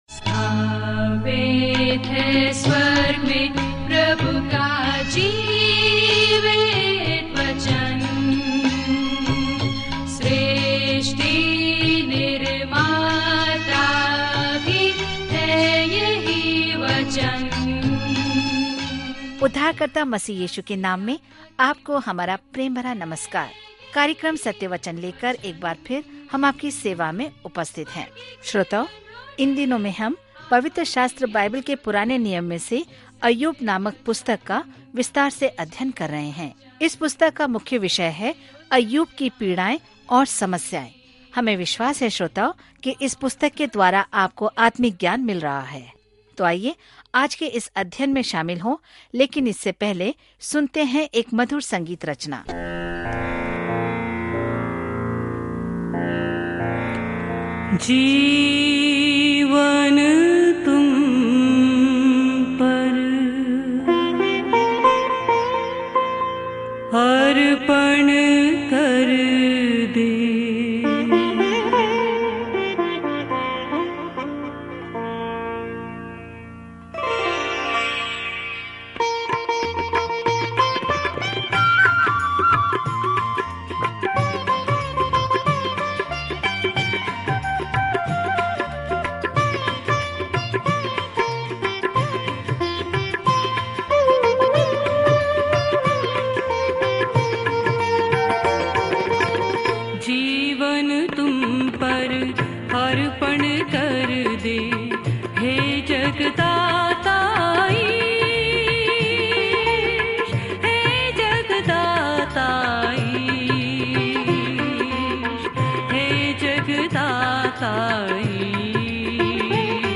पवित्र शास्त्र अय्यूब 4 अय्यूब 5:1-3 दिन 4 यह योजना प्रारंभ कीजिए दिन 6 इस योजना के बारें में इस स्वर्ग और पृथ्वी नाटक में, हम अय्यूब से मिलते हैं, एक अच्छा आदमी, जिस पर भगवान ने शैतान को हमला करने की अनुमति दी थी; हर किसी के मन में बहुत सारे सवाल होते हैं कि बुरी चीजें क्यों होती हैं। जब आप ऑडियो अध्ययन सुनते हैं और भगवान के वचन से चुनिंदा छंद पढ़ते हैं, तो जॉब के माध्यम से दैनिक यात्रा करें।